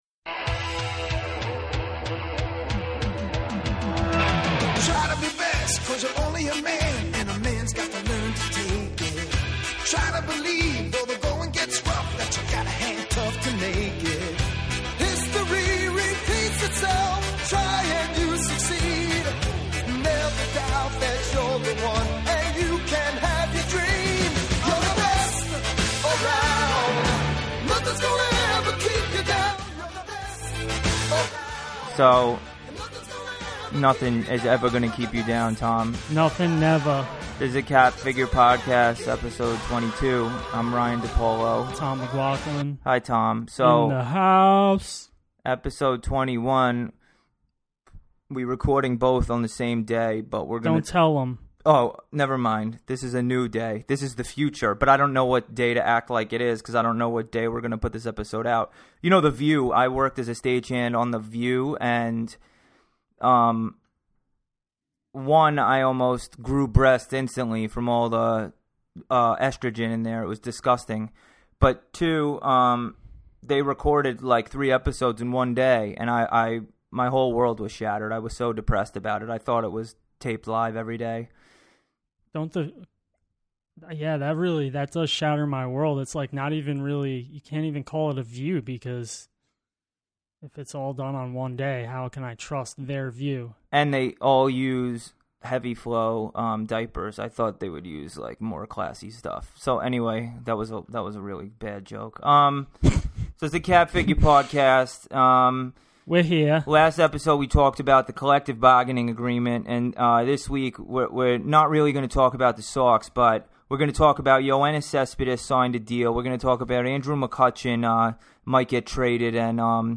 delivers a witty, unapologetic and unfiltered attitude to hosting the show